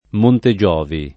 Montegiovi [ monte J0 vi ]